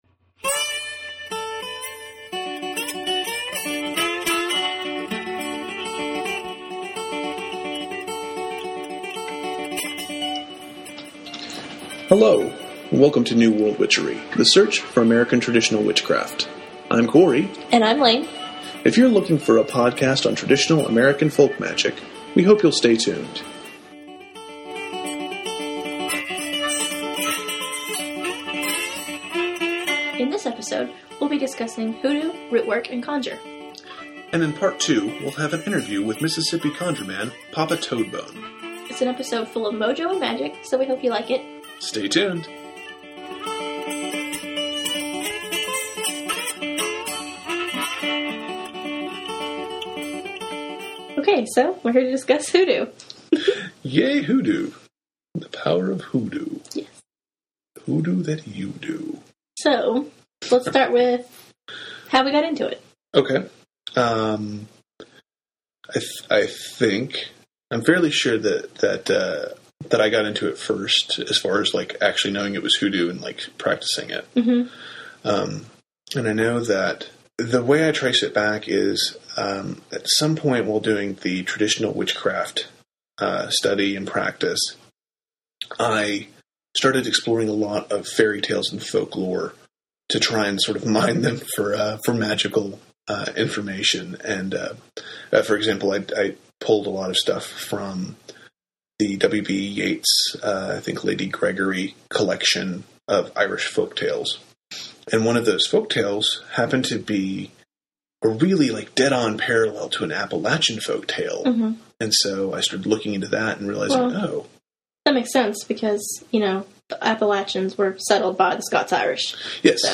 Podcast 6 – Hoodoo and an Interview